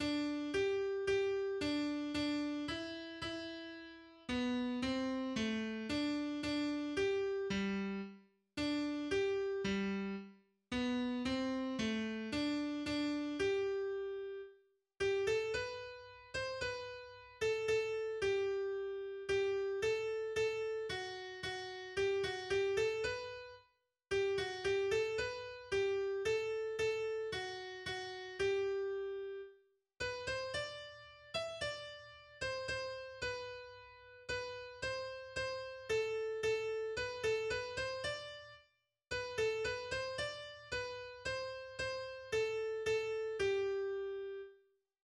Melodie zum Lied
3-stimmiger Kanon aus dem 18. Jahrhundert